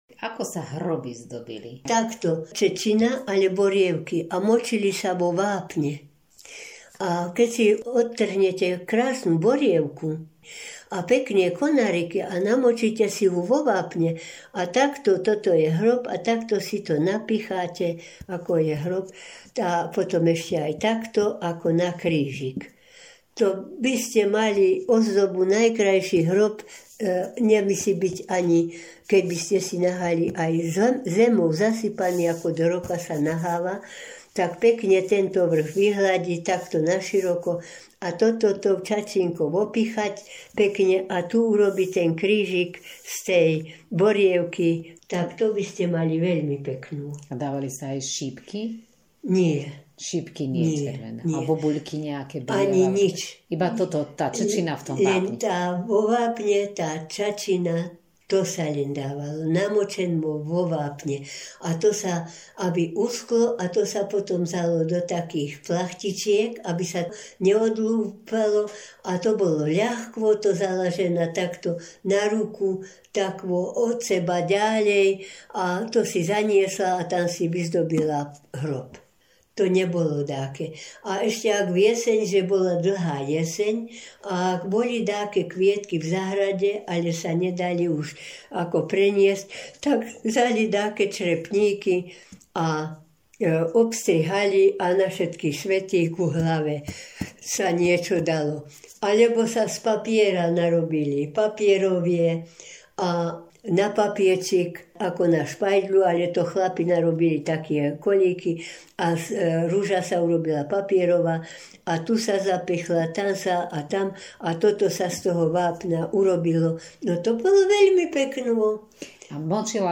Municipality of originKorytárky, okres Detva
Key words spomienkové dni